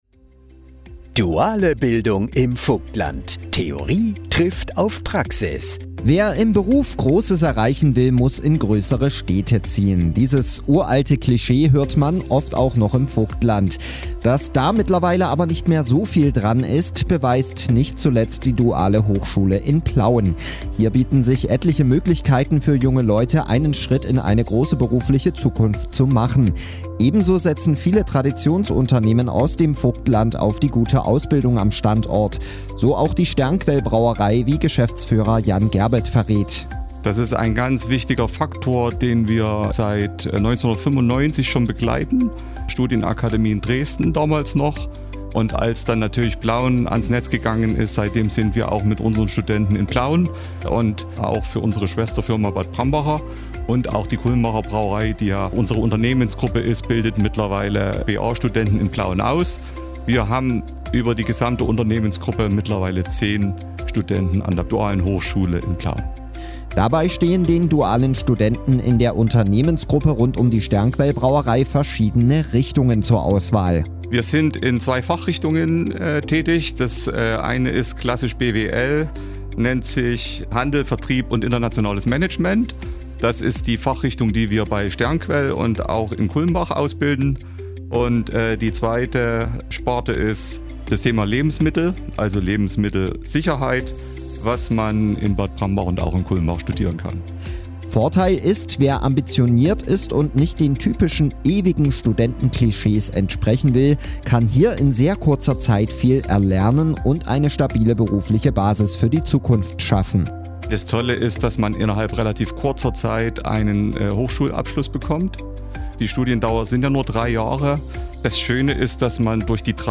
Interviews im Vogtlandradio mit Partnern und Unternehmen: